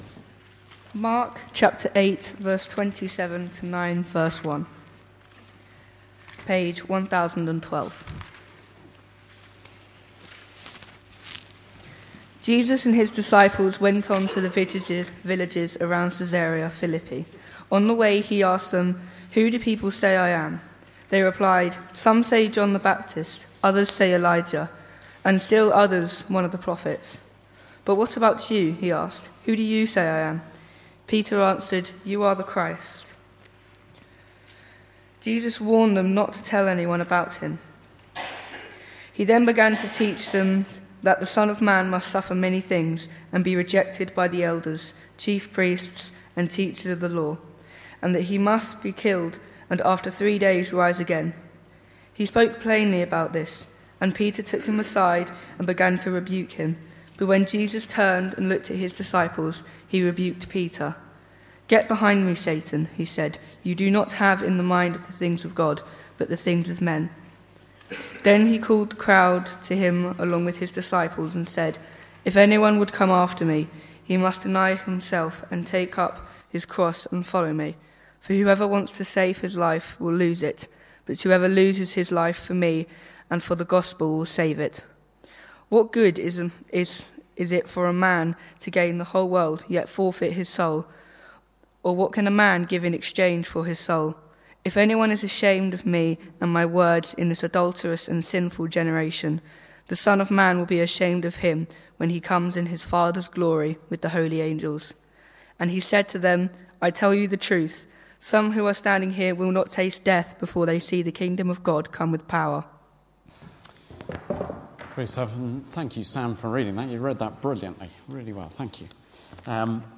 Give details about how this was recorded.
Mark 8:27-9:1 Service Type: Sunday Morning « Mission to Earth